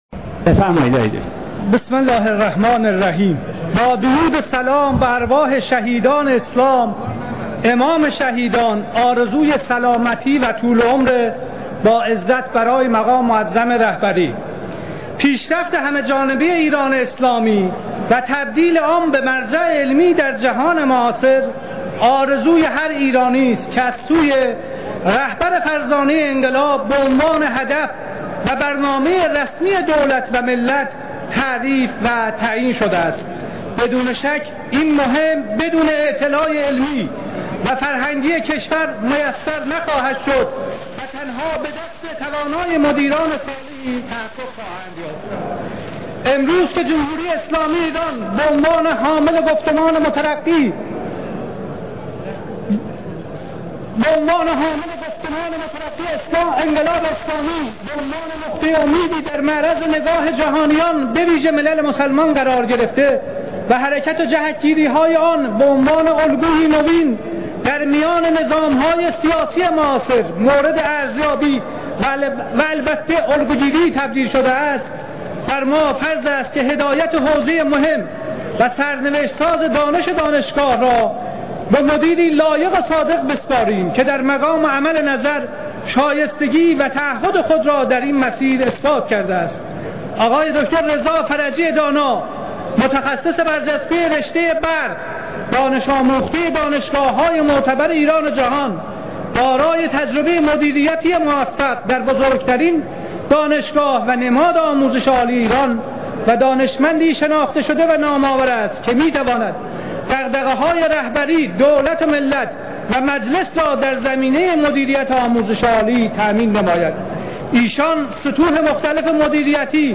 دریافت فایل نطق محسن علیمردانی در موافقت با وزیر پیشنهادی علوم با حجم